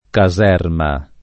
caserma [